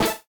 bloink.ogg